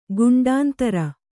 ♪ guṇḍāntara